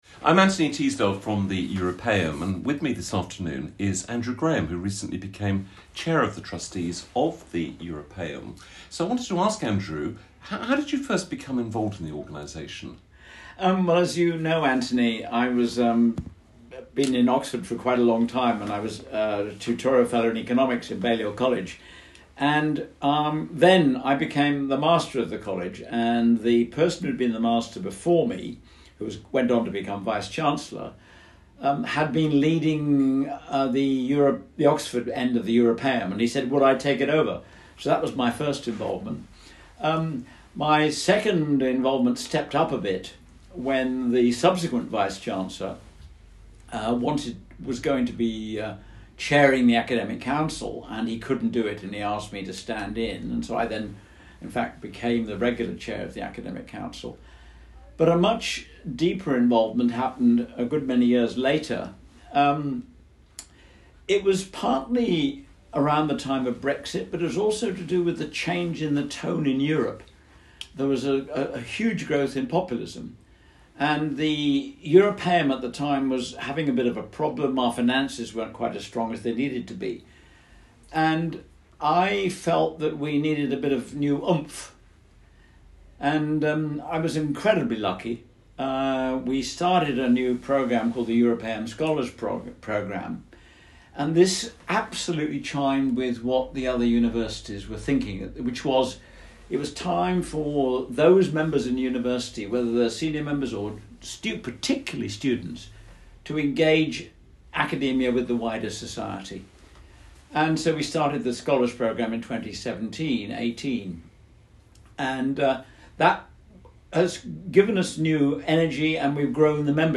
Europaeum Interviews